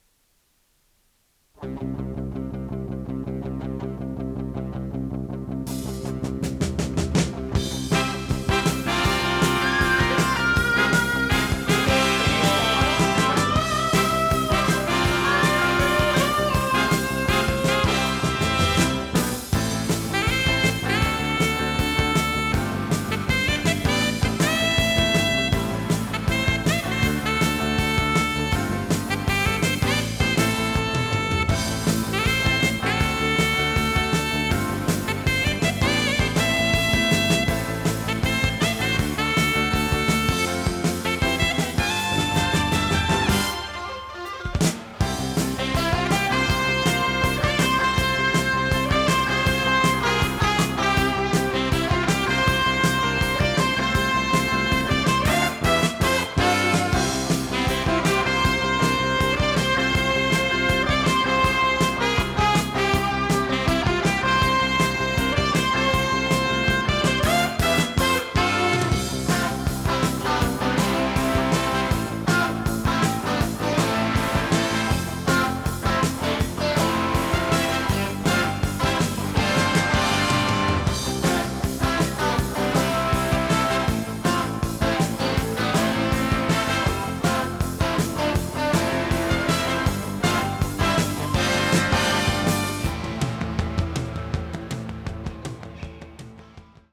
【フュージョン・ロック】★他機で録音したテープ　録音デッキ：TEAC C-3 48kHz-24bit 容量26.6MB